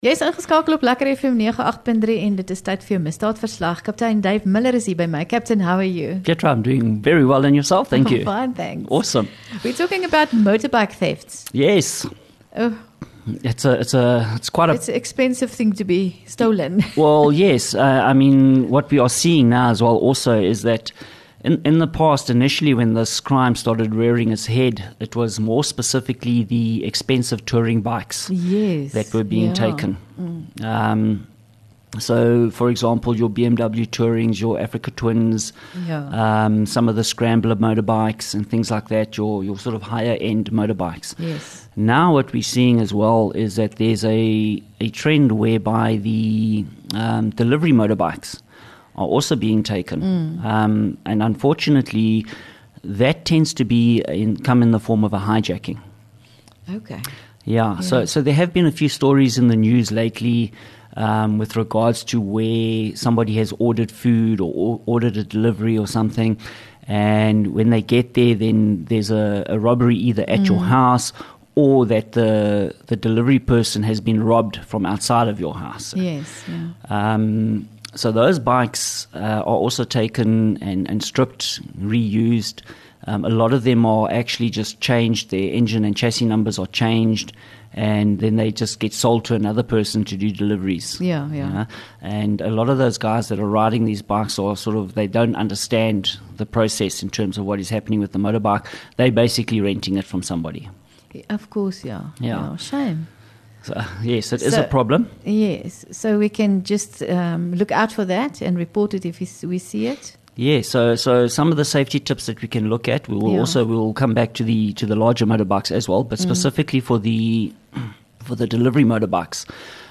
LEKKER FM | Onderhoude 2 Jul Misdaadverslag